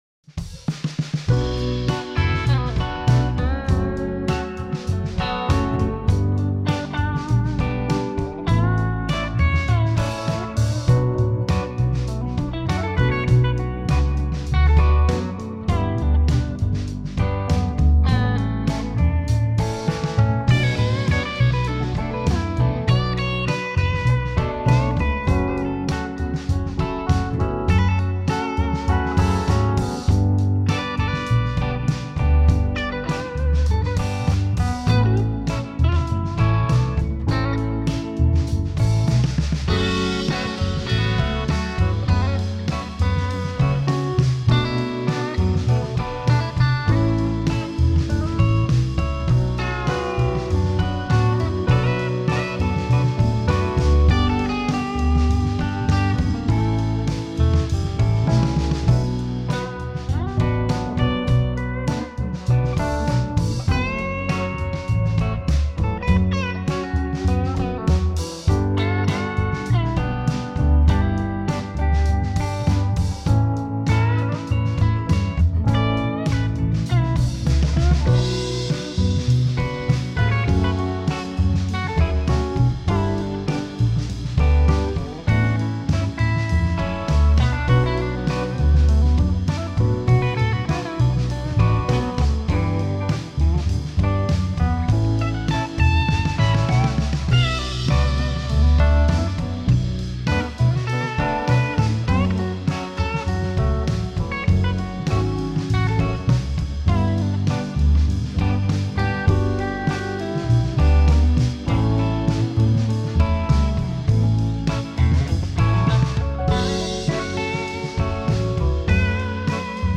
Home > Music > Blues > Bright > Smooth > Medium